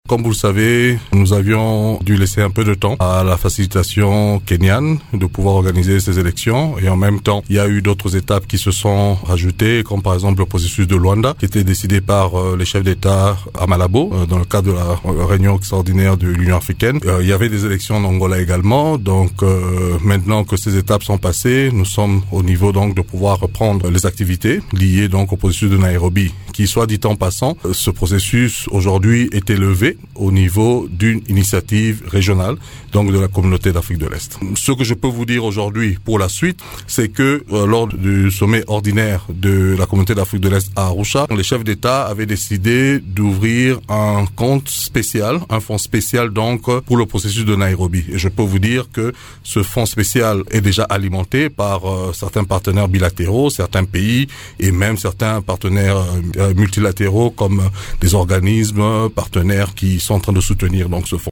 Dans une interview exclusive à Radio Okapi, il a indiqué que ce processus des pourparlers, élevé au niveau d’une initiative régionale, bénéficie d’un fond spécial pour sa mise en œuvre, tel que décidé par les chefs d’Etats lors du sommet ordinaire d’Arusha (Tanzanie).